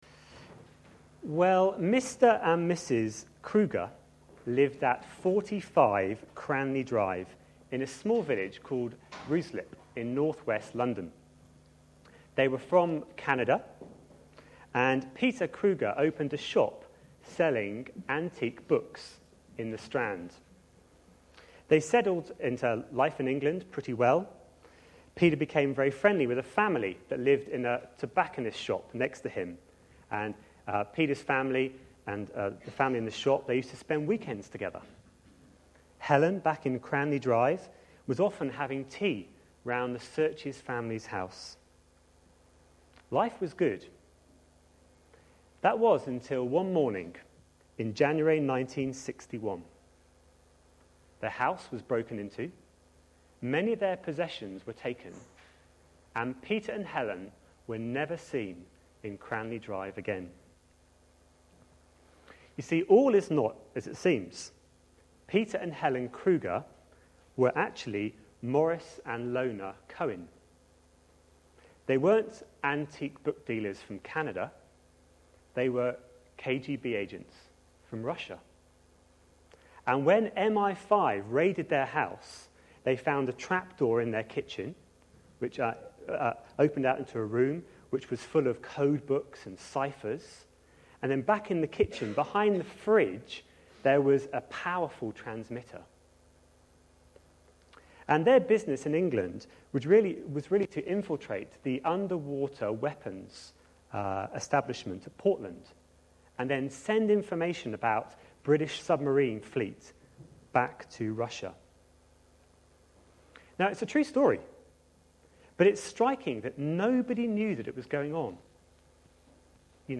A sermon preached on 3rd April, 2011, as part of our Ephesians series.